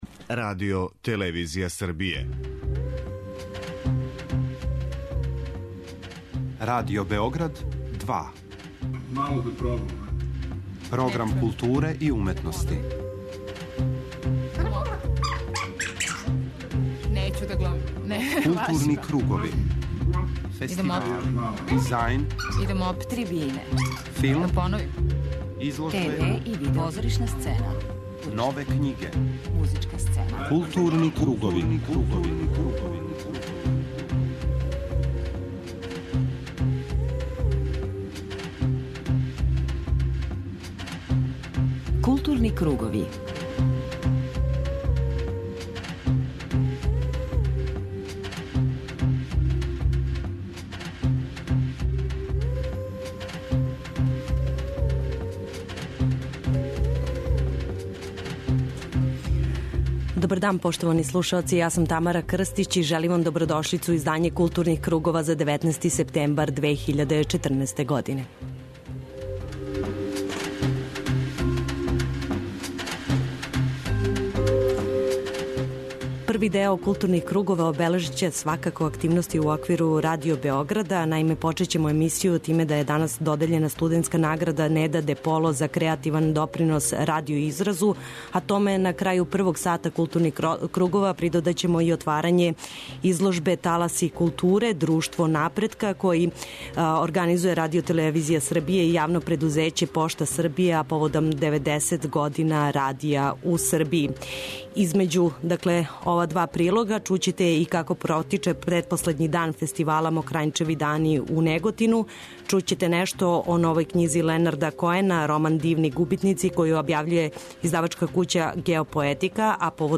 У другом делу слушаћете репортажу о округлом столу "Српска поезија данас", одржаном у огранку САНУ у Новом Саду.